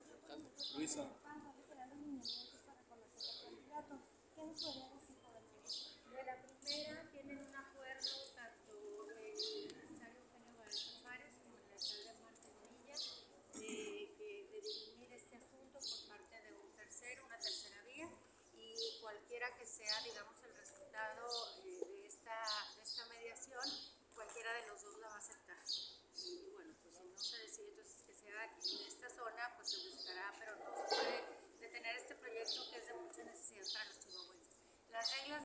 Así lo dijo en entrevista luego de la inauguración de la licenciatura de medicina en la Universidad del Valle de México realizada hoy en el campus de la dicha institución.